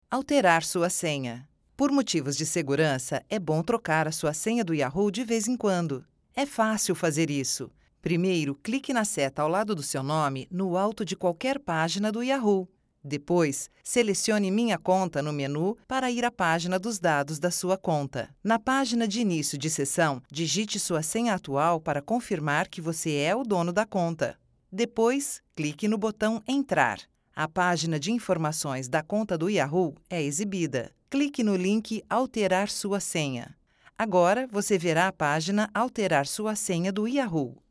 Voiceover Portugues Brasileiro, voiceover talent.
Sprechprobe: Industrie (Muttersprache):